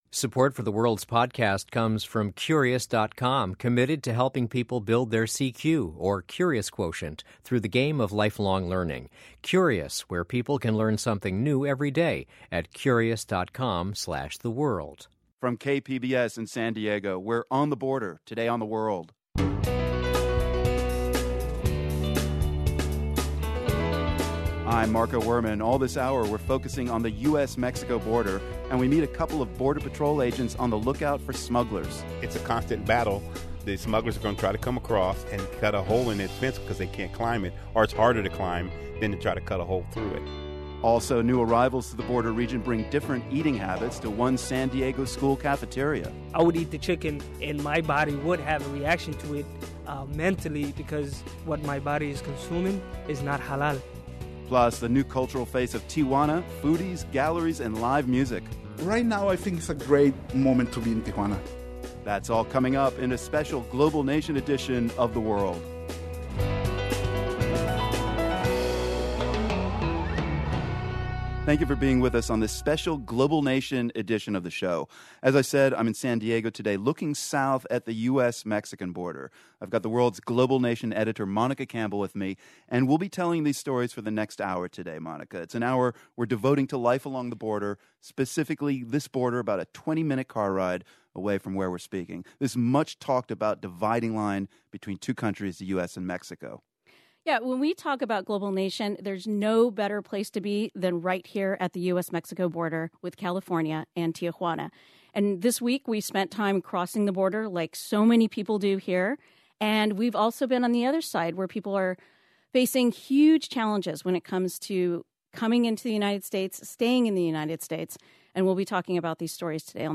We're taking The World on the road today with a special show out of station KPBS in San Diego.